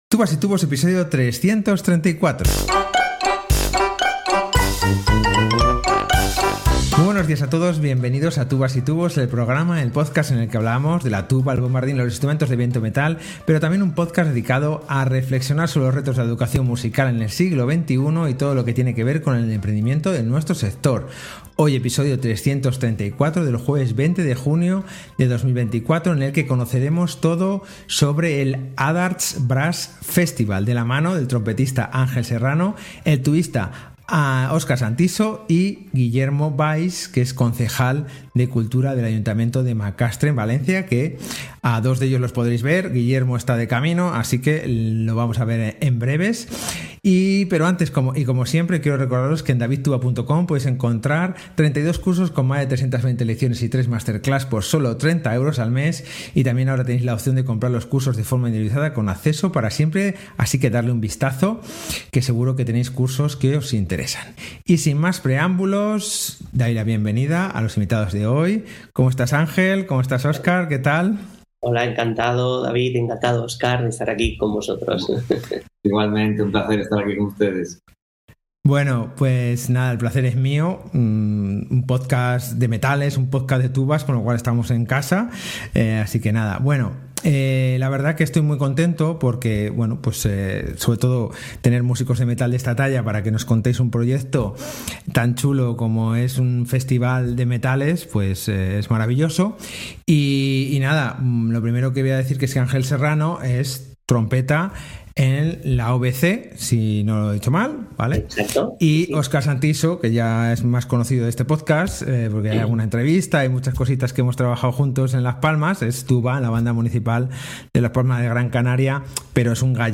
Entrevista sobre el Adarts Brass Festival que se celebra en Macastre